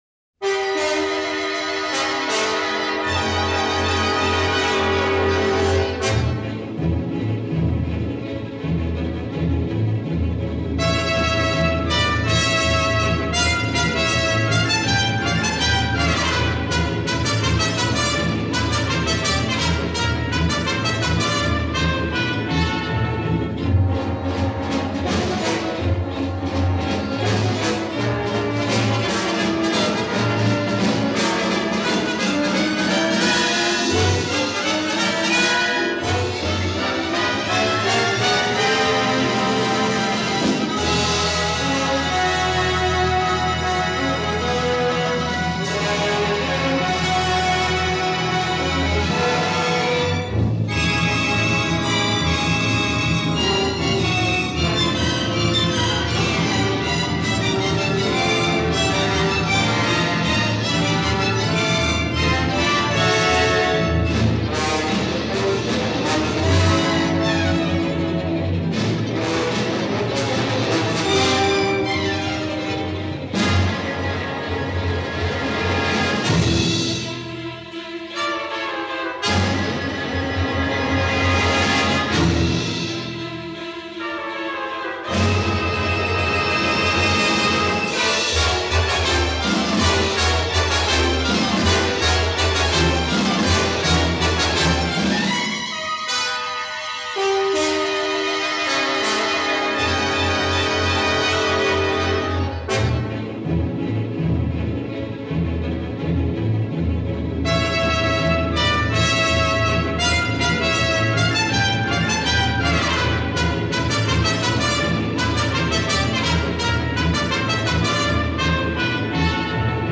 There’s a strong “Rite of Spring” component to this piece.